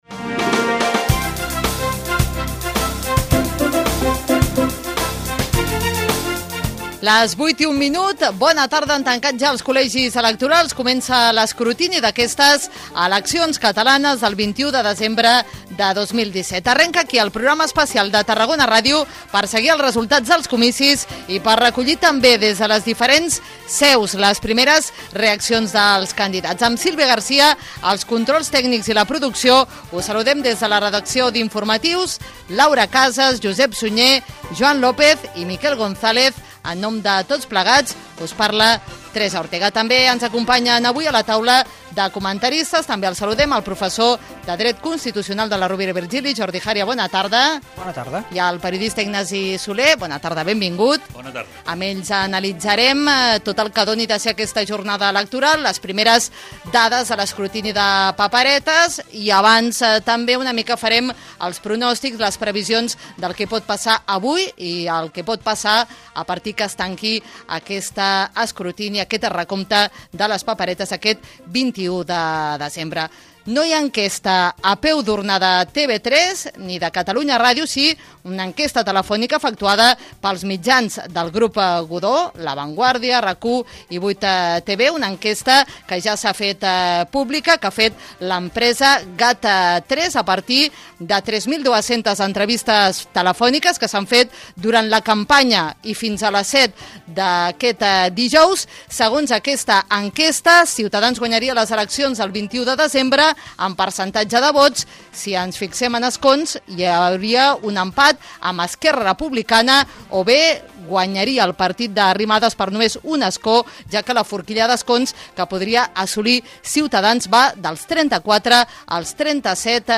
Informatius: nit electoral - Tarragona Ràdio, 2017